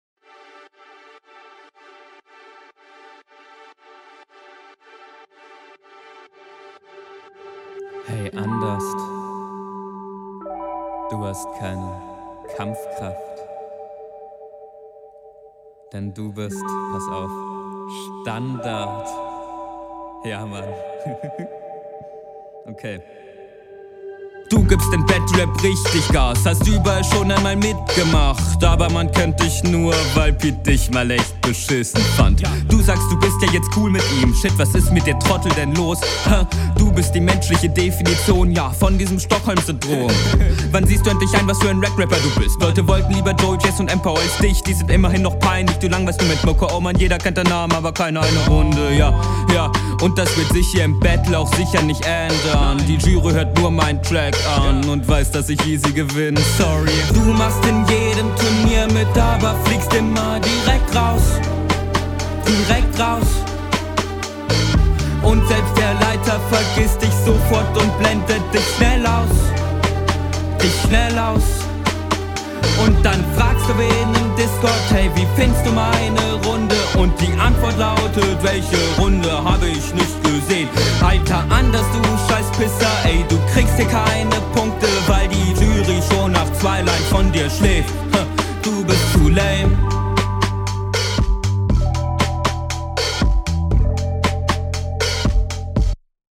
Beat hat meiner Meinung nach nicht wirklich Battlevibes.